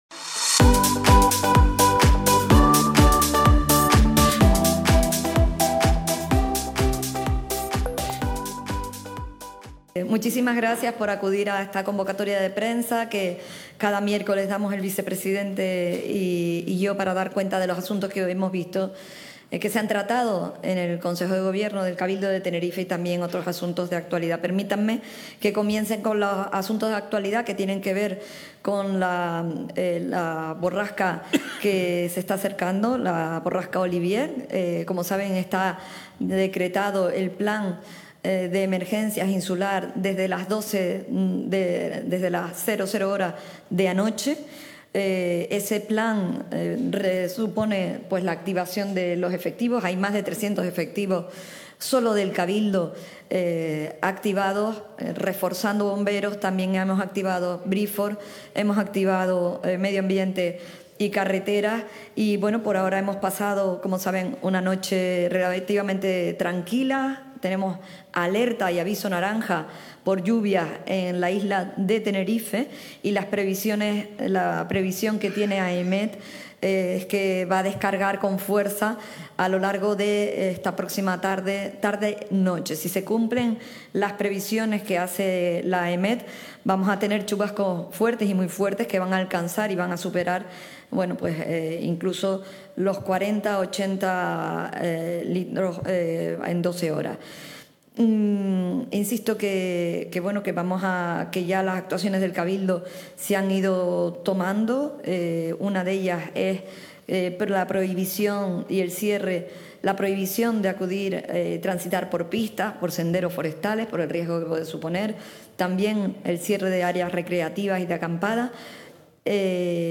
Recientemente emitido: Este miércoles 9 de abril, a partir de las 11.00 horas, emisión en directo de la rueda de prensa de presentación de los acuerdos del Consejo de Gobierno.